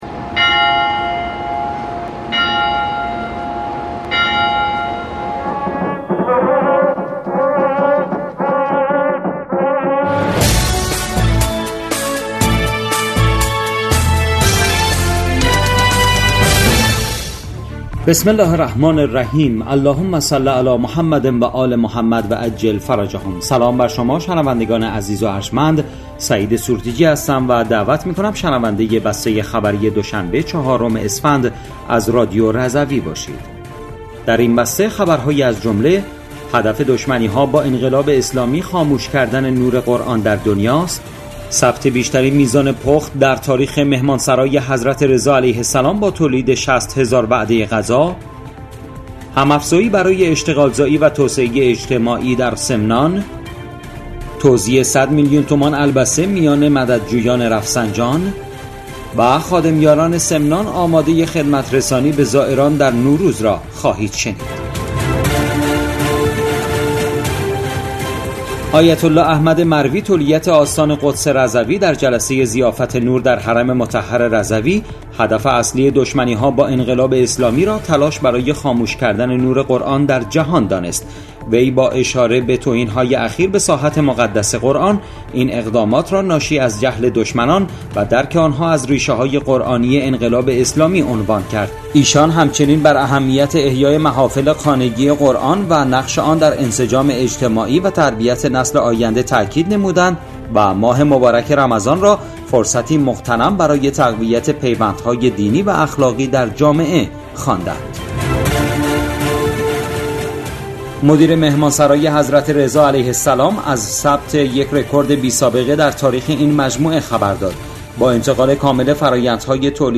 بسته خبری ۴ اسفند ۱۴۰۴ رادیو رضوی؛